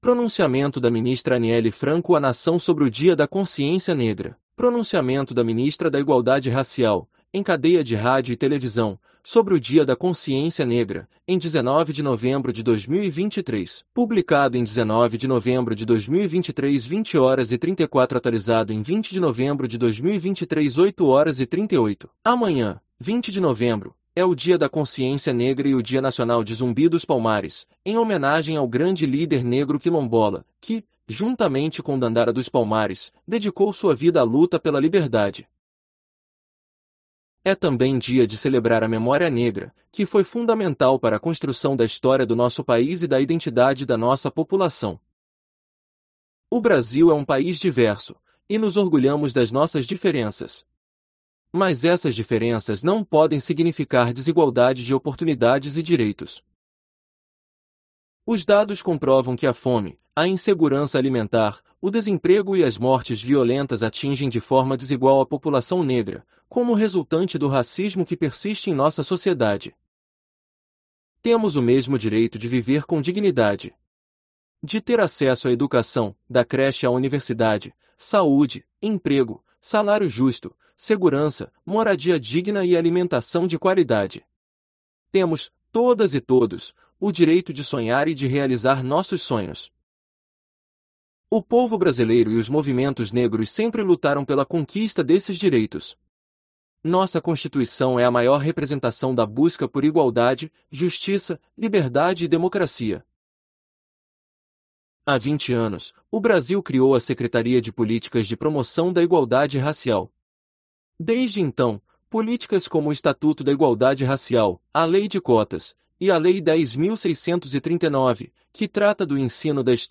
Pronunciamento da ministra Anielle Franco à nação sobre o Dia da Consciência Negra
Pronunciamento da ministra da Igualdade Racial, em cadeia de rádio e televisão, sobre o Dia da Consciência Negra, em 19 de novembro de 2023